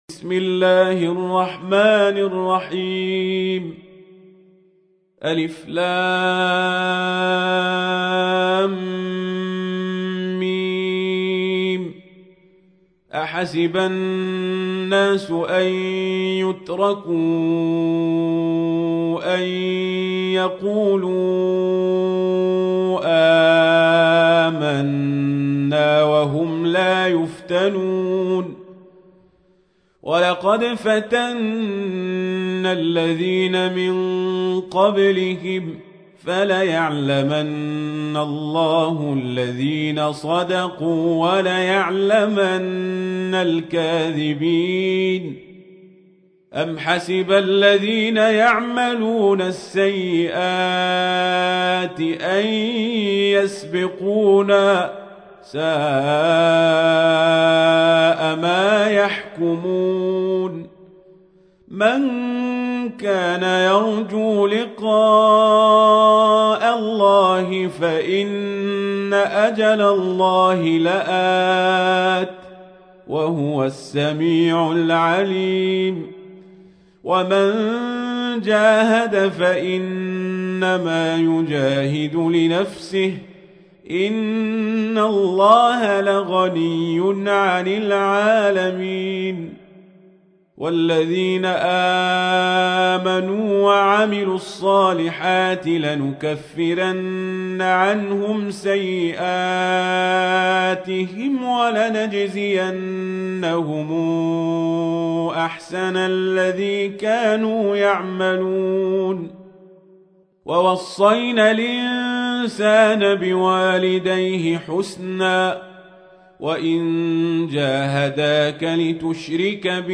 تحميل : 29. سورة العنكبوت / القارئ القزابري / القرآن الكريم / موقع يا حسين